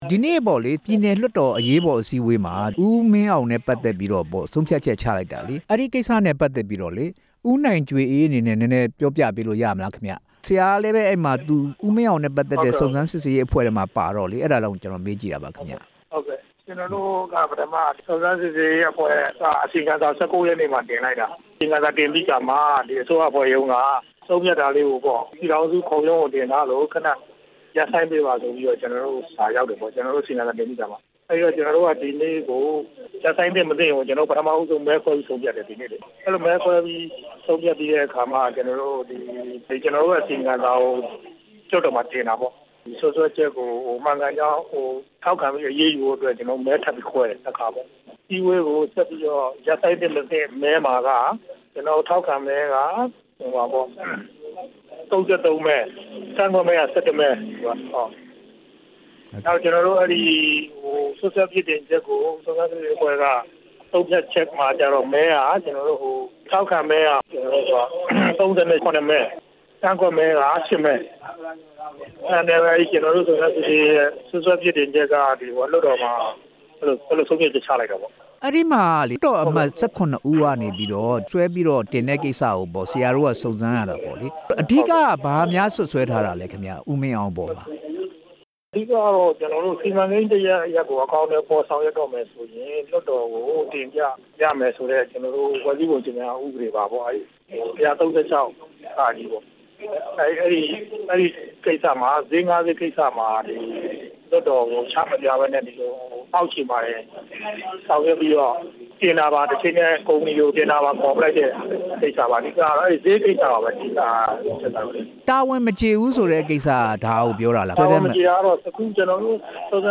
စည်ပင်ဝန်ကြီး ဦးမင်းအောင် နုတ်ထွက်ရေး မဲခွဲဆုံးဖြတ်မှု မေးမြန်းချက်